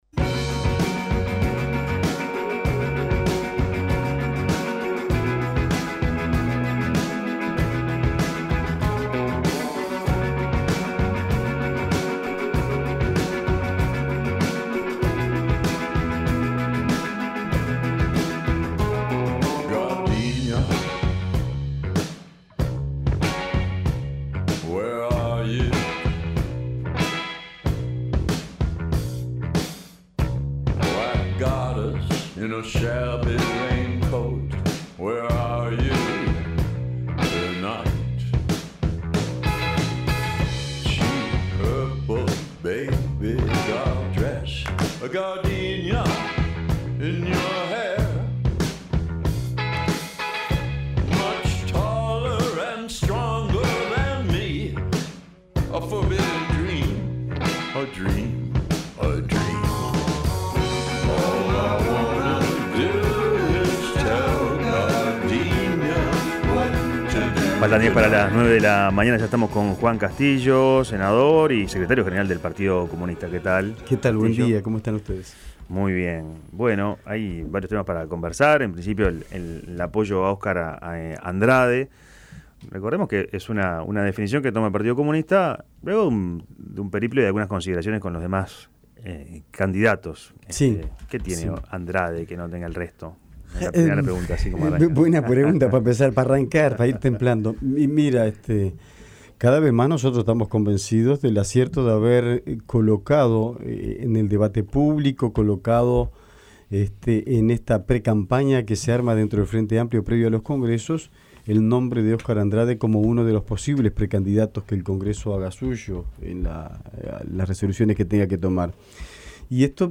El dirigente comunista dijo a La Mañana de El Espectador que el Frente Amplio debe apuntar a defender y profundizar los cambios, pero sostuvo que es necesario mejorar la distribución de la riqueza, y tener una mirada autocritica para analizar aquello que no salió bien, como el tema de la Seguridad Pública.